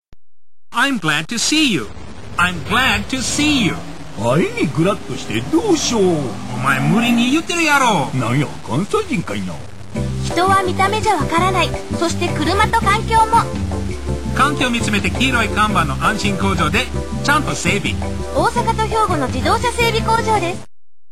外人(英語の時間のようにリピート)